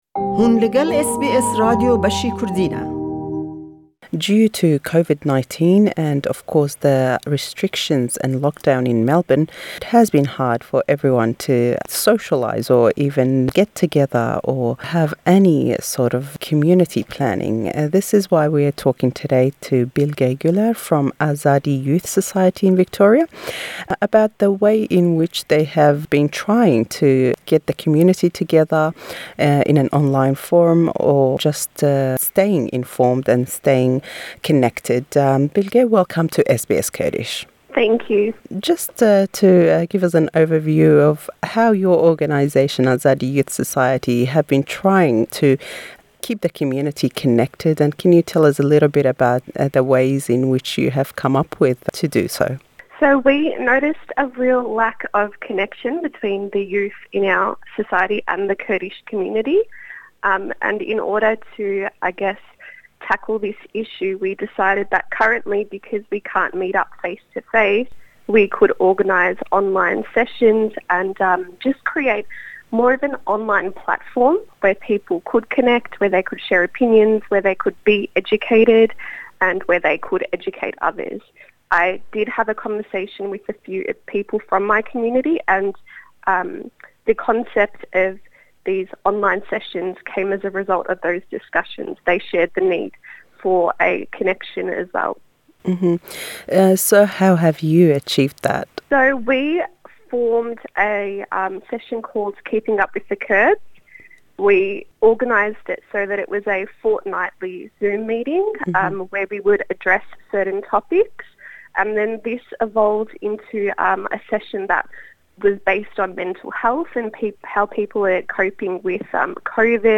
Em hevpeyvîne be zimanî Înglîzî ye.